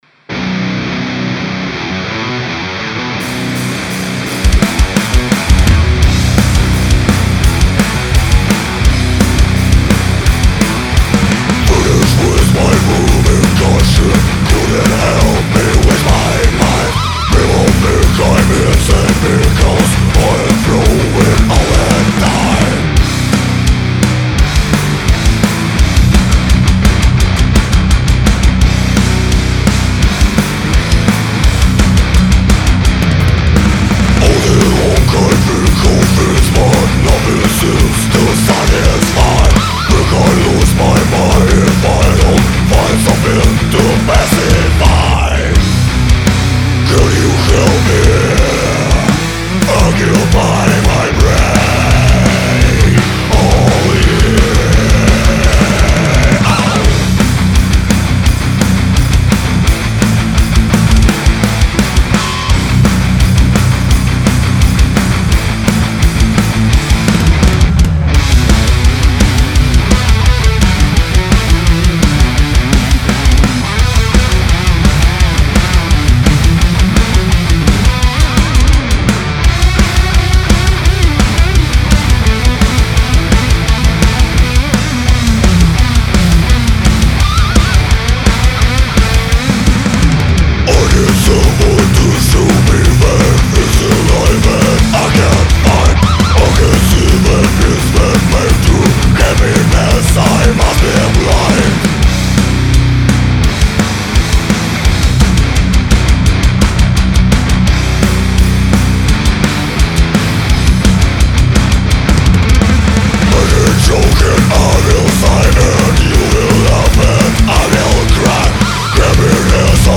Конечно вокал все подпортил)))